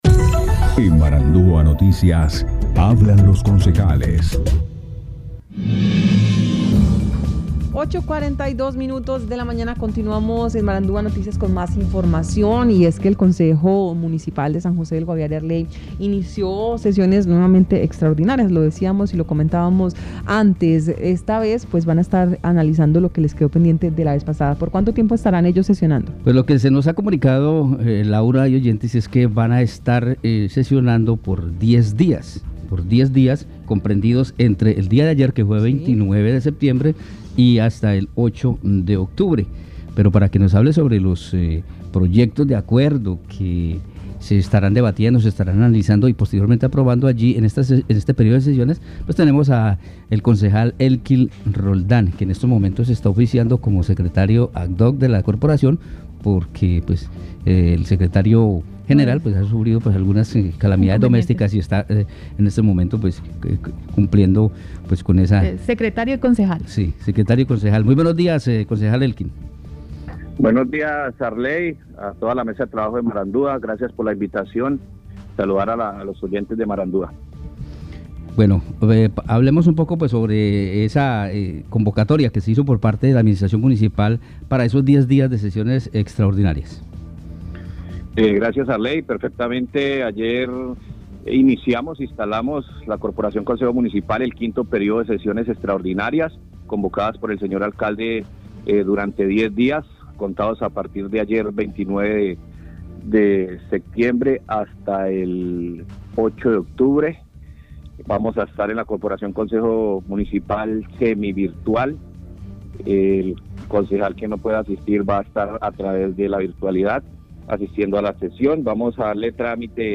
Escuche a Elkin Roldan, concejal de San José del Guaviare, Guaviare.